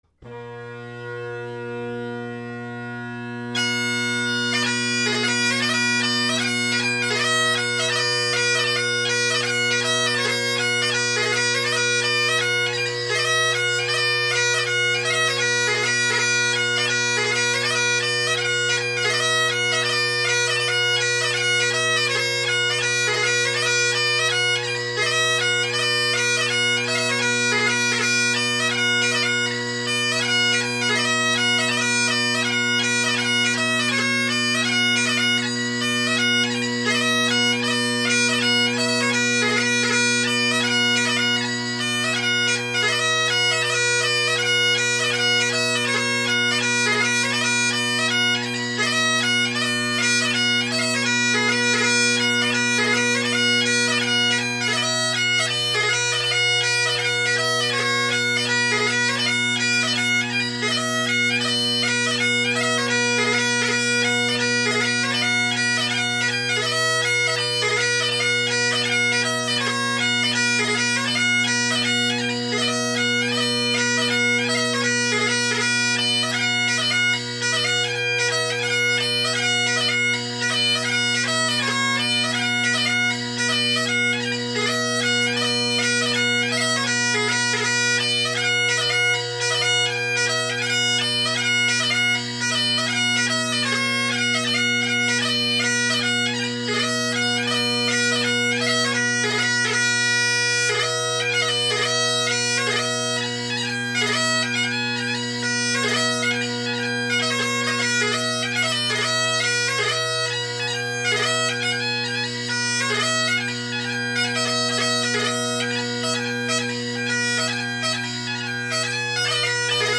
Great Highland Bagpipe Solo
It’s a very bass dominant sound at the moment.
Cowal Gathering, Inveraray Castle, Lochiels Awa to France – facing mic